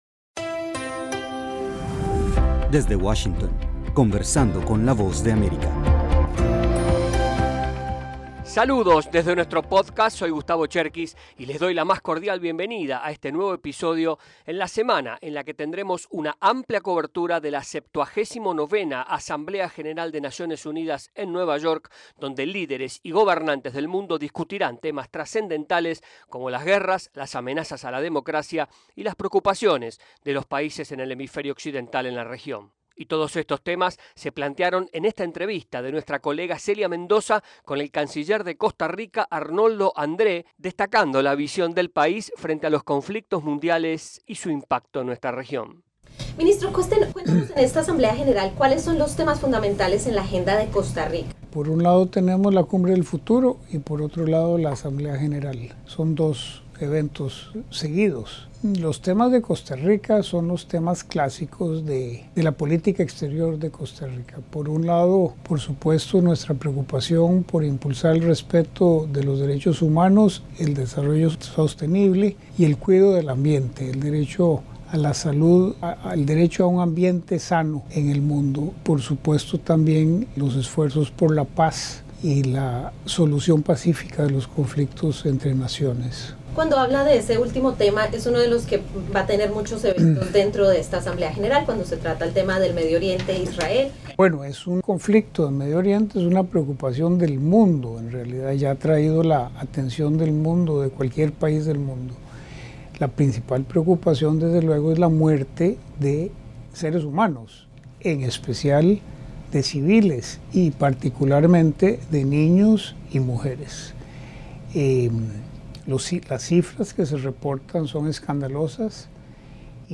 El canciller de Costa Rica, Arnoldo André Tinoco, conversó con la VOA en el contexto de la 79 Asamblea General de la ONU, en Nueva York, sobre temas clave como la presencia militar rusa en la región y los conflictos bélicos en curso en la Franja de Gaza y Ucrania.